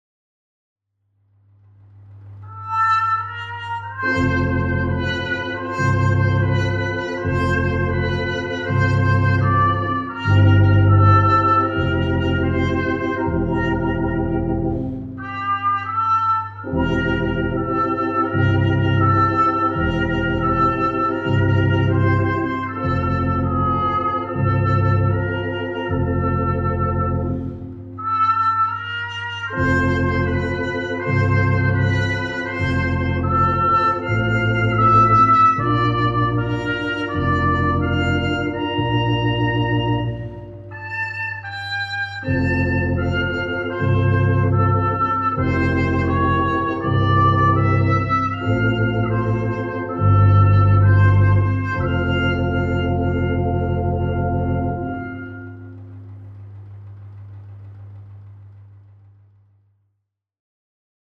MERZ Beruhigungsmusik
trompeteorgel.mp3